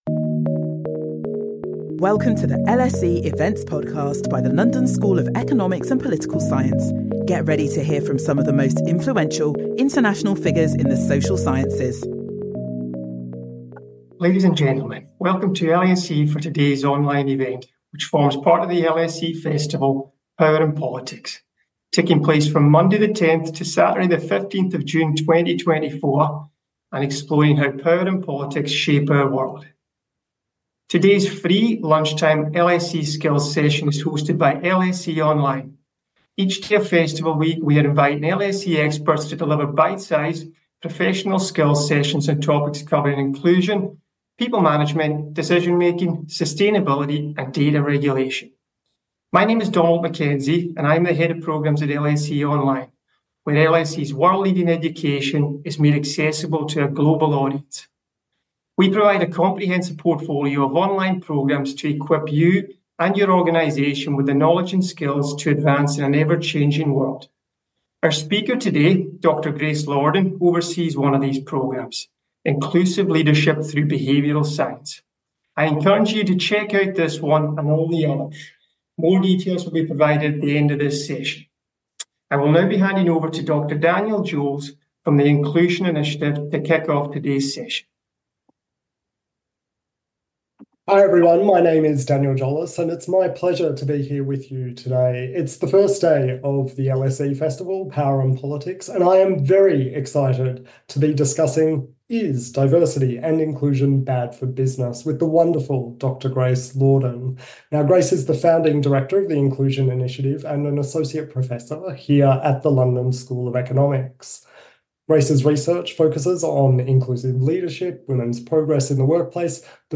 | LSE Festival Skills